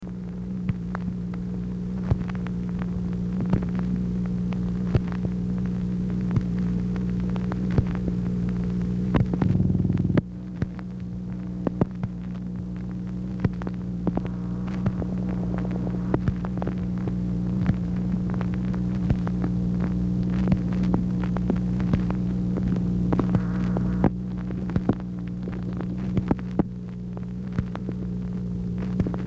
Telephone conversation # 1467, sound recording, MACHINE NOISE, 1/22/1964, time unknown | Discover LBJ
ORIGINAL BELT DAMAGED
Format Dictation belt